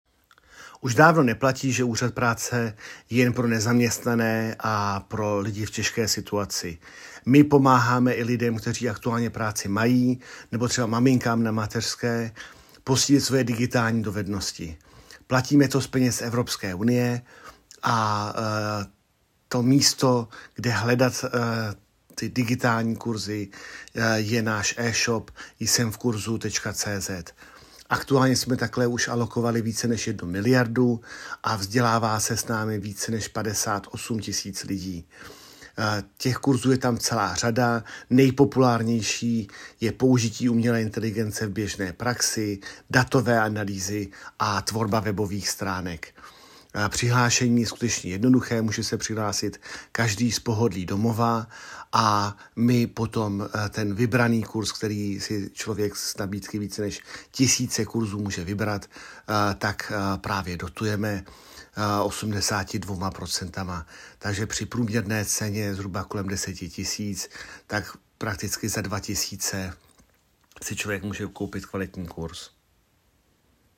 KOMENTÁŘ_AUDIO_GŘ_Daniel Krištof_digi vzdělávání